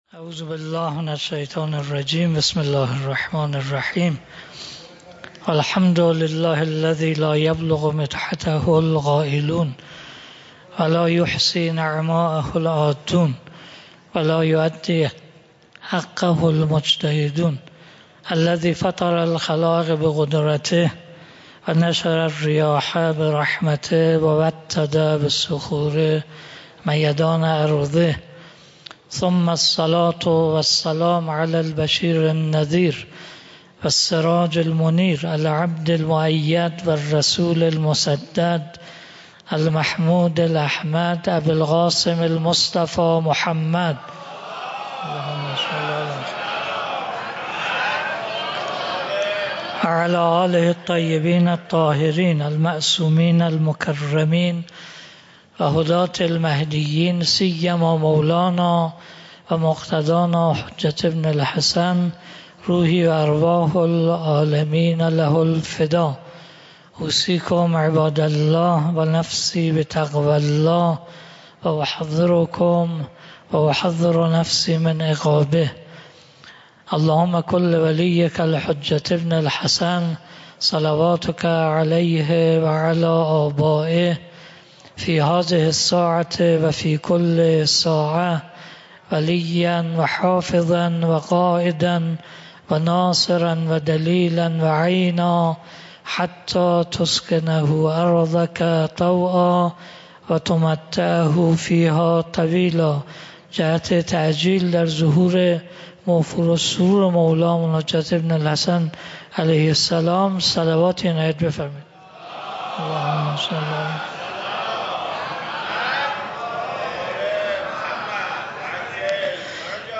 خطبه-اول-1.mp3